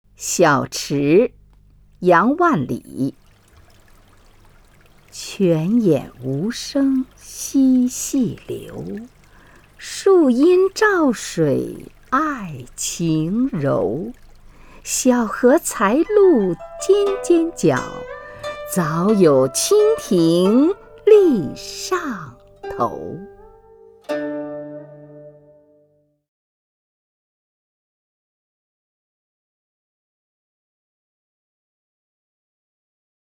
虹云朗诵：《小池》(（南宋）杨万里) （南宋）杨万里 名家朗诵欣赏虹云 语文PLUS